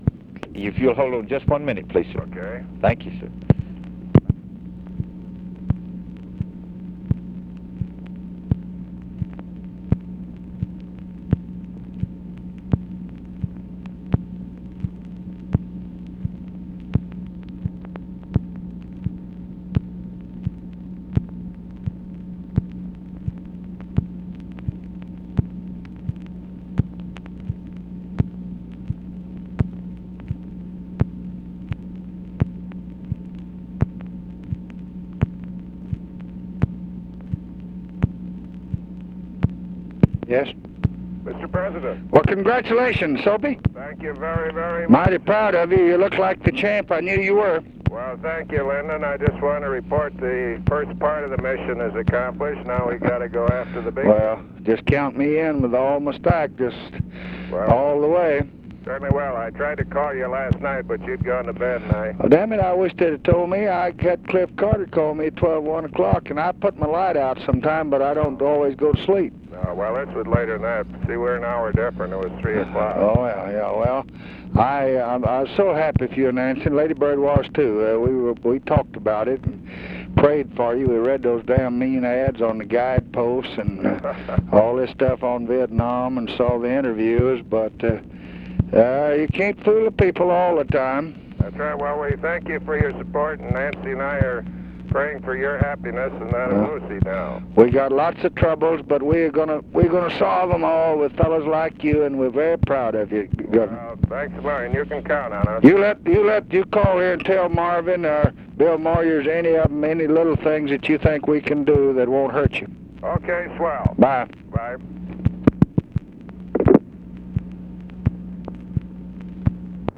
Conversation with G. MENNEN WILLIAMS and UNIDENTIFIED MALE, August 3, 1966
Secret White House Tapes